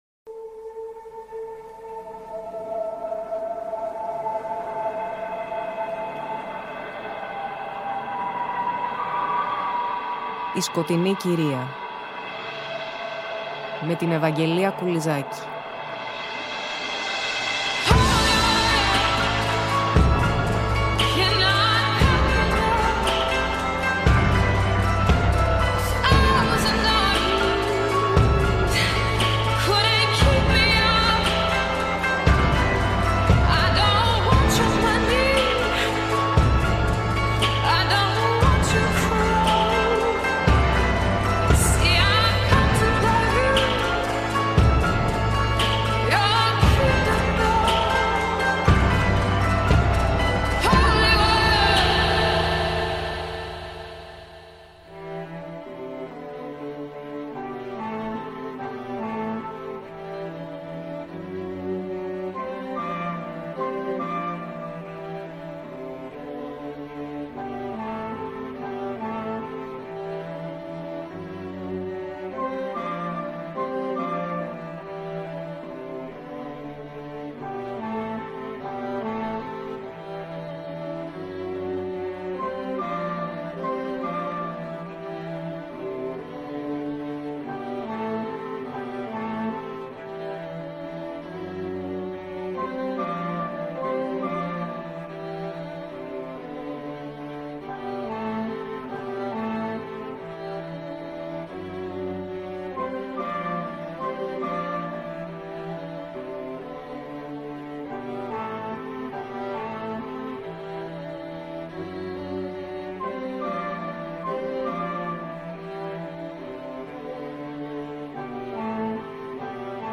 Πλαισιώνουμε μουσικά με Satie , Webern , Schoenberg και Berg .